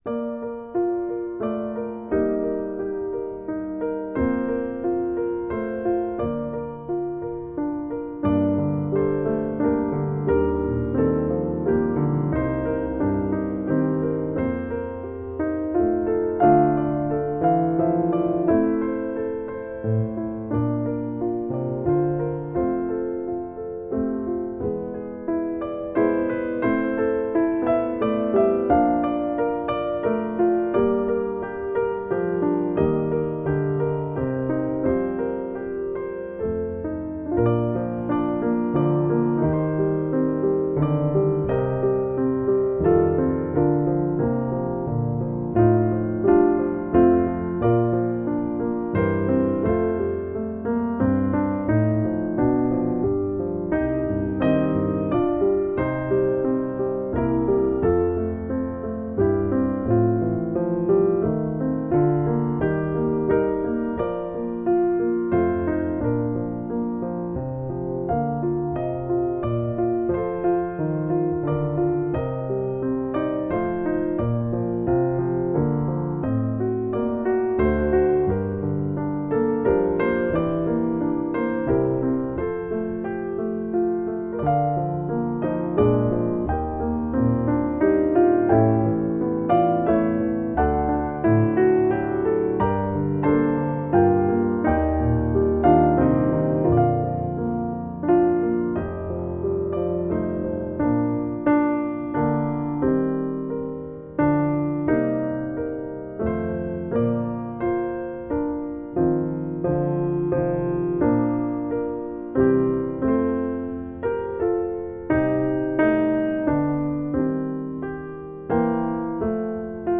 Voicing: 1 Piano 4 Hands